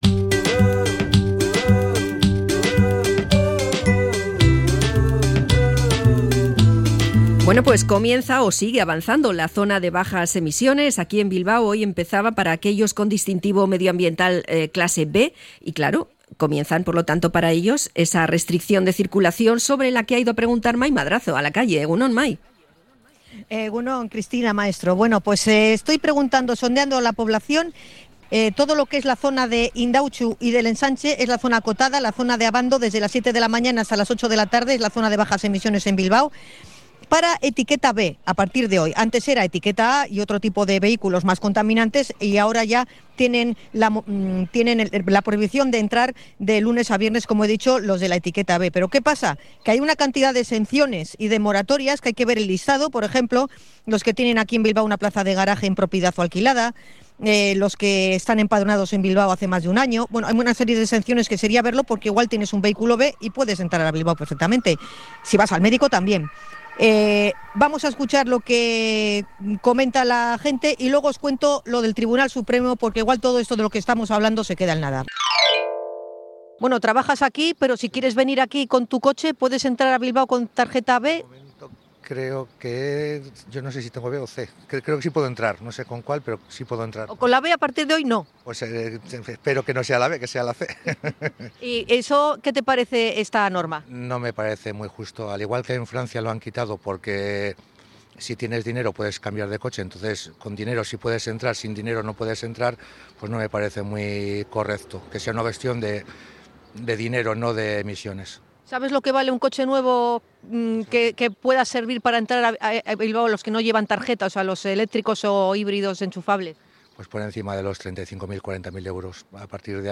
Hemos salido a la calle para sondear la opinión de los Bilbaínos sobre la ZBE
ENCUESTA-ZBE-BILBAO.mp3